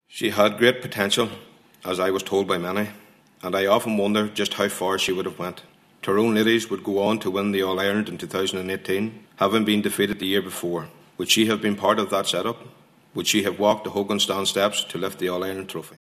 During today’s hearings